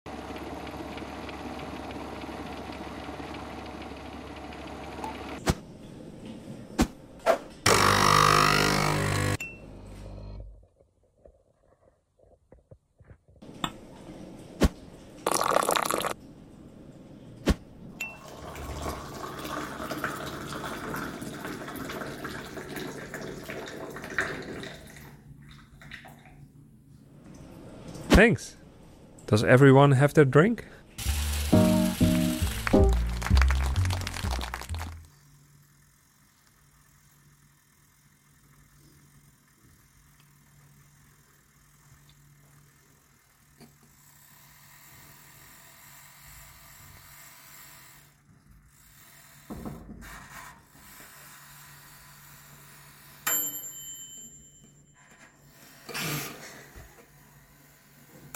🔊 Does anyone want a sound effects free download By rustylake 9 Downloads 2 months ago 58 seconds rustylake Sound Effects About 🔊 Does anyone want a Mp3 Sound Effect 🔊 Does anyone want a drink? 👀 To make The Mr. Rabbit Magic Show feel truly immersive, we had the amazing team @ Driftwood Audio come by to capture authentic office sounds: from our office water boiler to our very own Rusty Lake coffee machine!